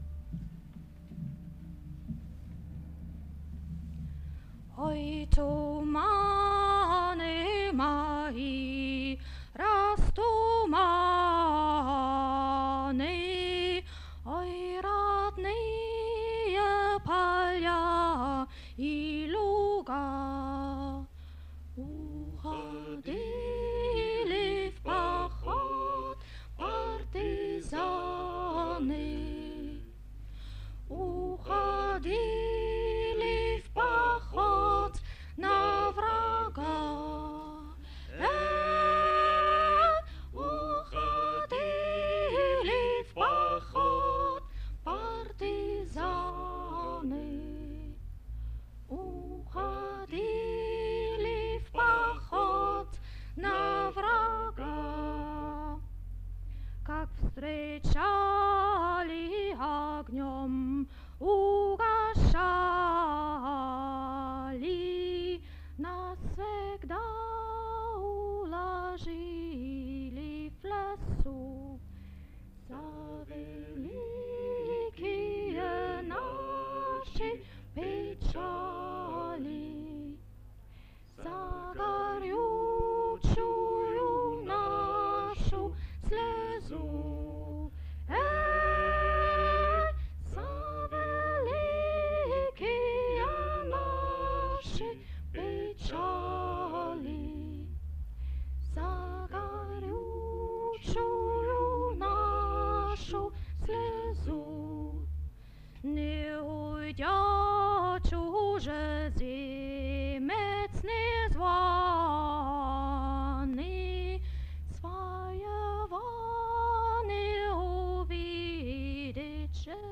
Ой тумани1984 SUSI - SU Singe / Moskau / Rucksack-Programm 02Радуга/RadugaМЭИ1984